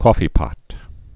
(kôfē-pŏt, kŏfē-)